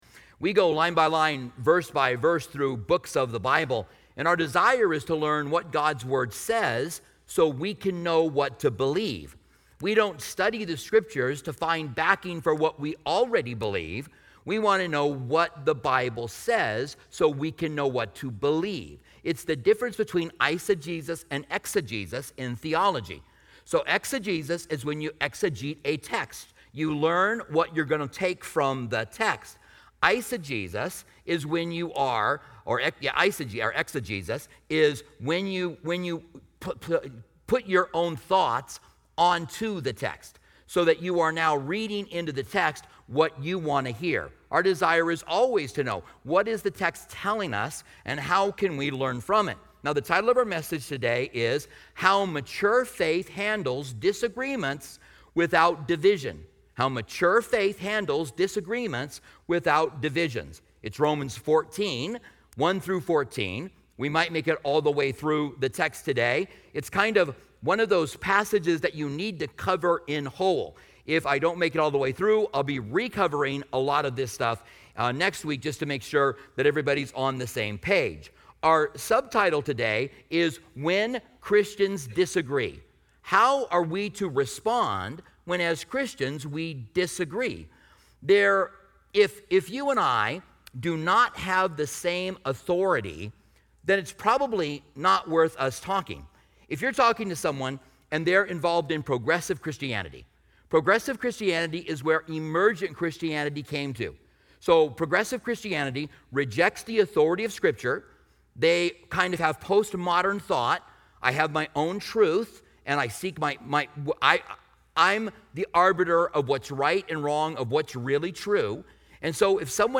This sermon highlights the importance of grounding our beliefs in Scripture through exegesis, understanding the Bible's authority, and addressing disagreements with grace.